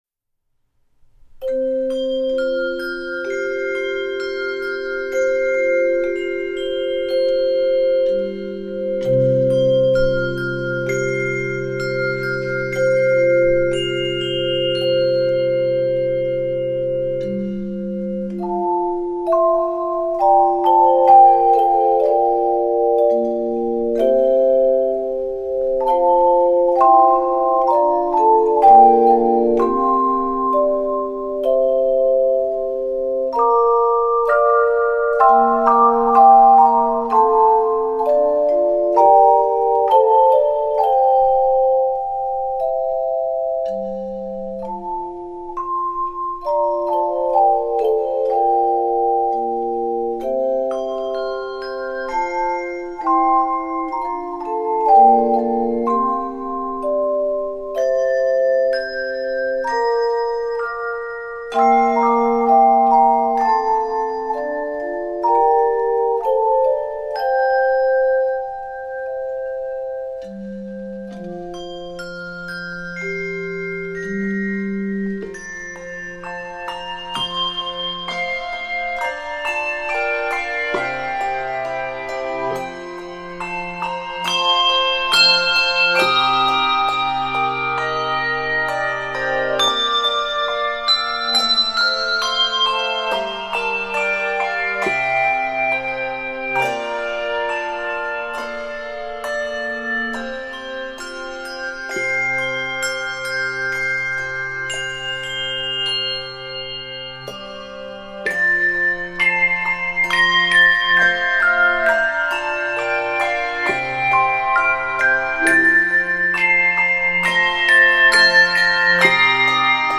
subtle, elegant musical tapestry